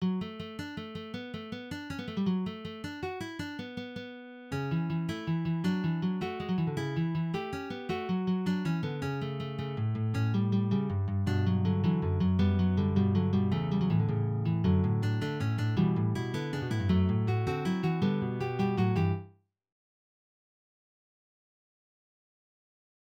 Started a new fugue today, it’s based on a traditional Irish jig:
connaughtmans-rambles-fugue.wav